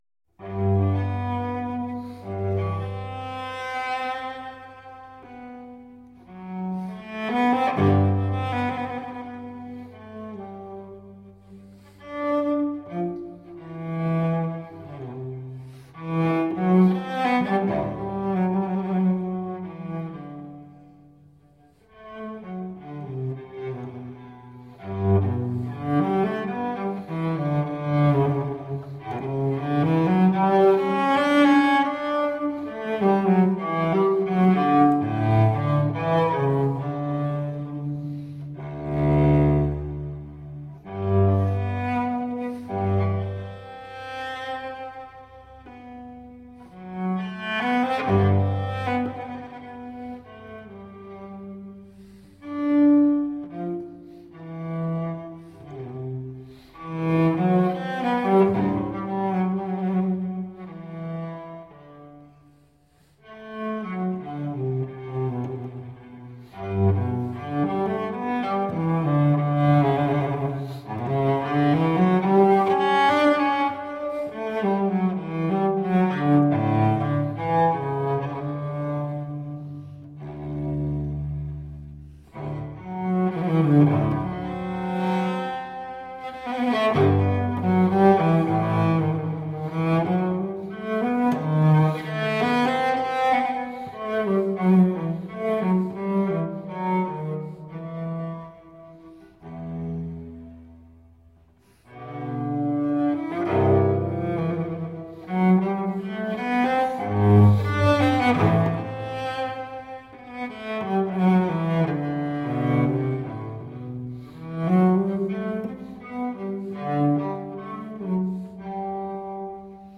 Cellist extraordinaire.